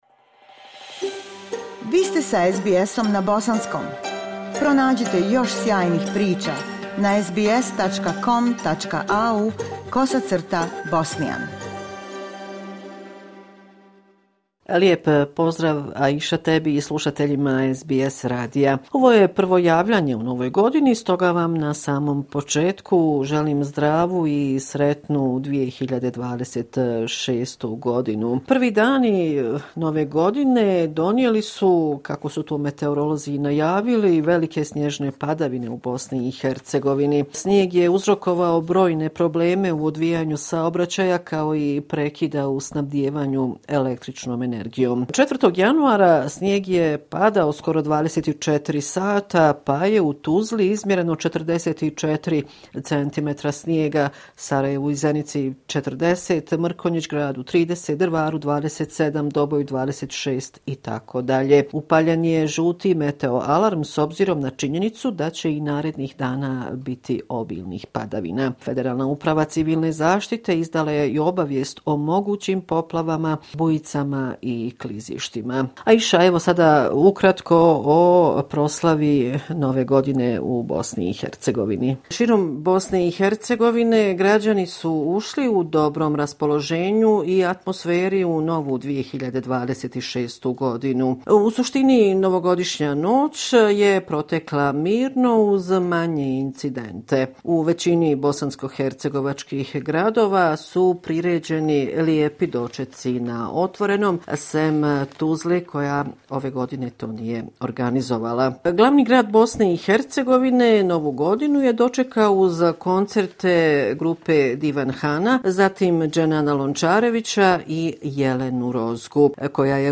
Sedmični izvještaj